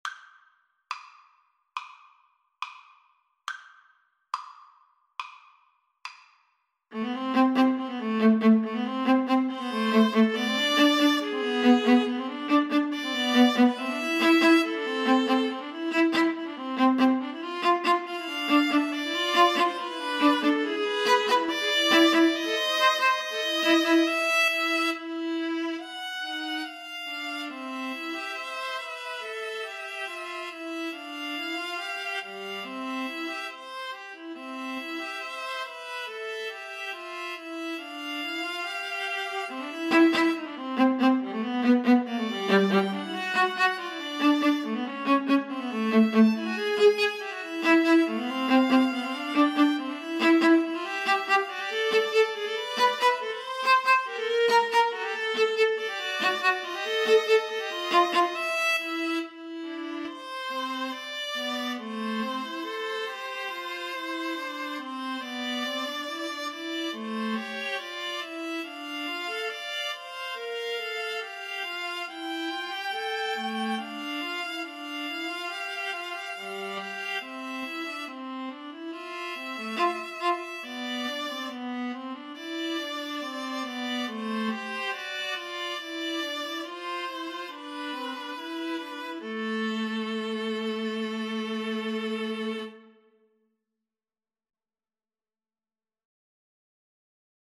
Viola 1Viola 2Viola 3
= 70 Andante
4/4 (View more 4/4 Music)
Classical (View more Classical Viola Trio Music)